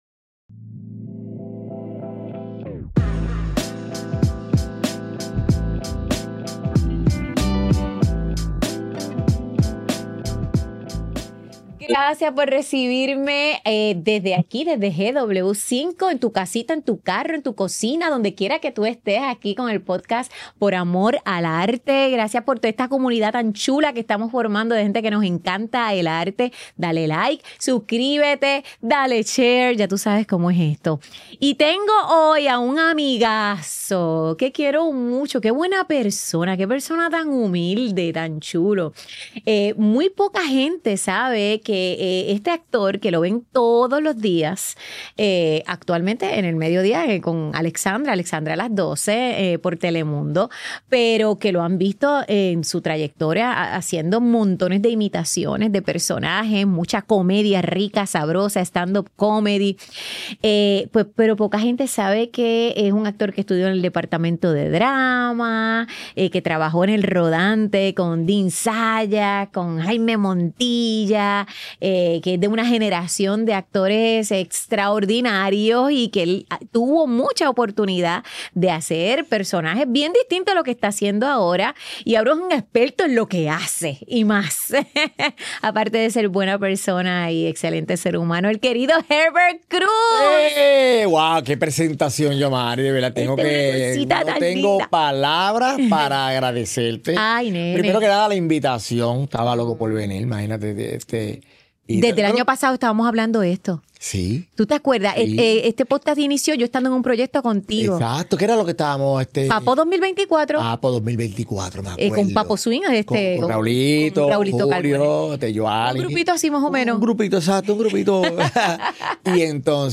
Grabado en GW-Cinco Studio para GW5 Network Stylist